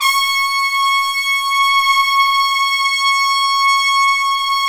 Index of /90_sSampleCDs/Roland LCDP06 Brass Sections/BRS_Tpts mp)f/BRS_Tps Swel %wh